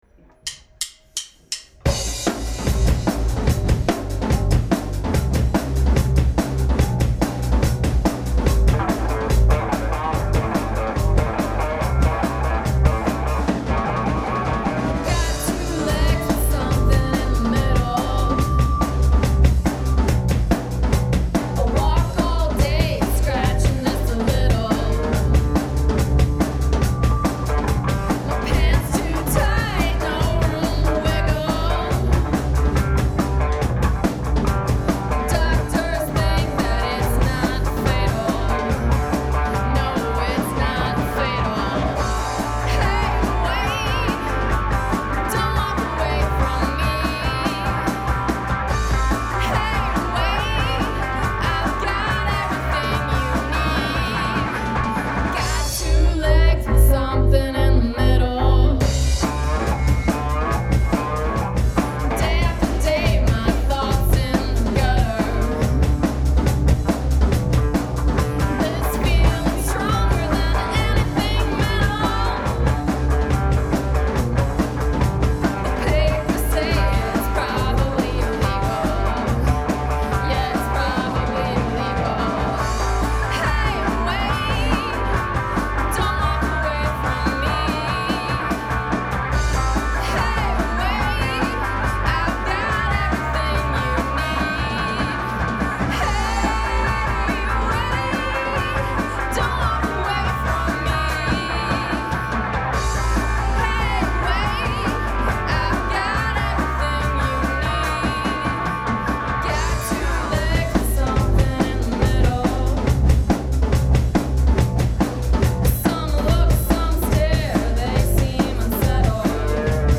Anyway, Something In the Middle is probably one of our most political songs (other than our very visible presence as women playing rock music) on our line-up.
bass player and vocalist
lead guitarist and vocalist
The recording is homemade, live at our studio. It’s not as polished as our previous recordings, but I’m digging the rawness.  We used this really handy recorder that fits right in your pocket,  Olympus LS10.